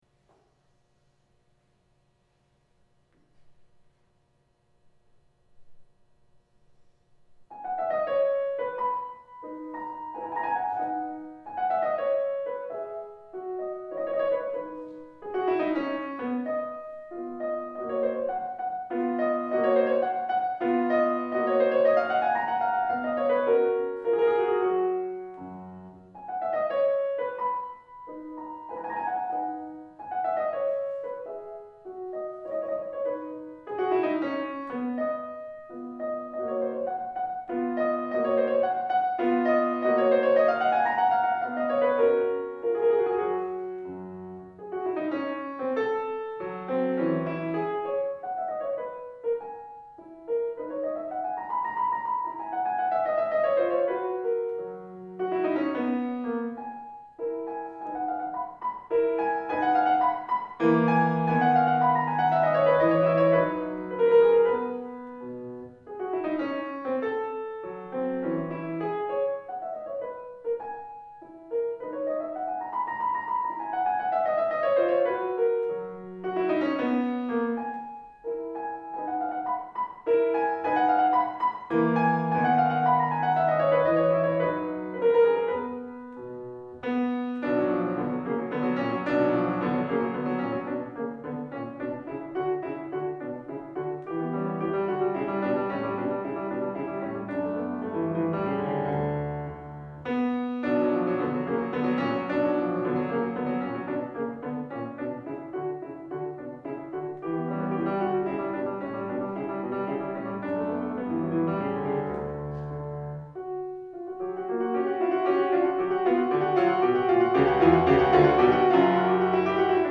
A sampling of my solo and chamber music recordings: